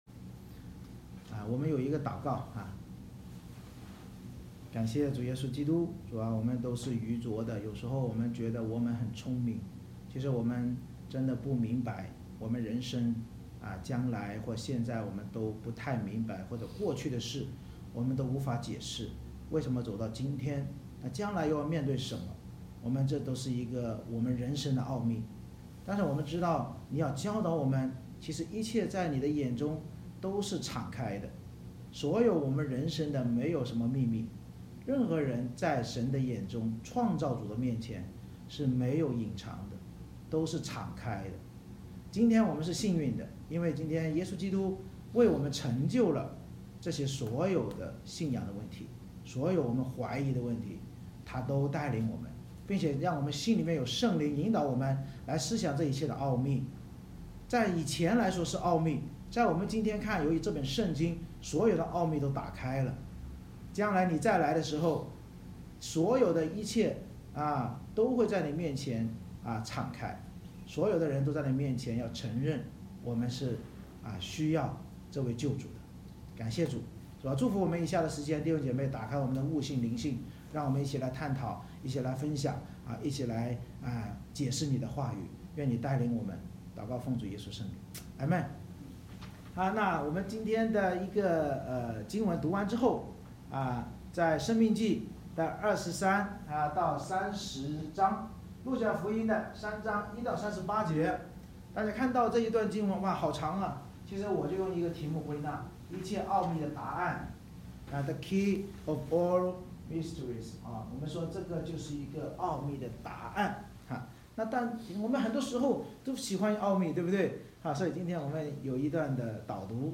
每次崇拜以经文诗歌赞美开始，接着让大人与孩子轮流上台以接力方式读3-4章经文（中/英文）或角色扮演，并简单分享，然后由牧师按《圣经导读新唱365》的内容进行归纳解释与应用，最后再唱经文诗歌来回应。
一切奥秘的答案（《圣经导读新唱365》式的读经与讲道）
Service Type: 主日崇拜